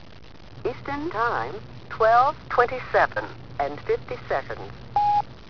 Registrazioni sonore di happening Fluxus